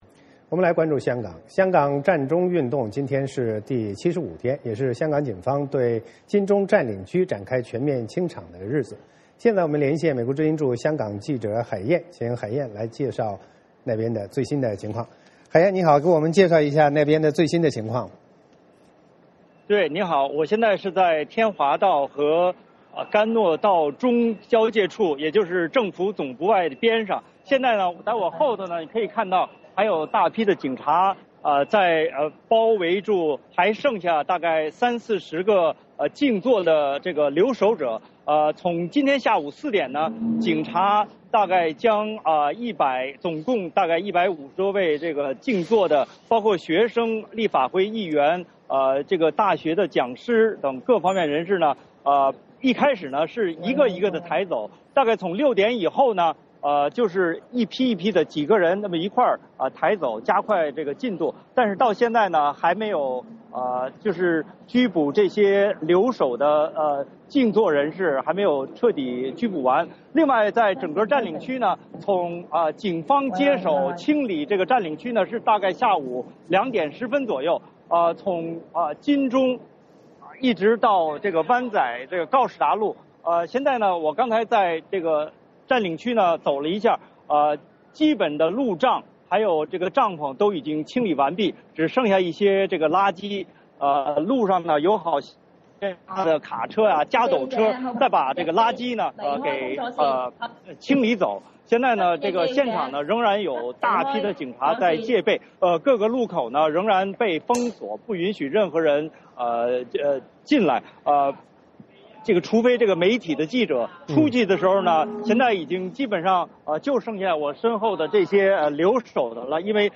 VOA连线：香港警方对“占中”营地清场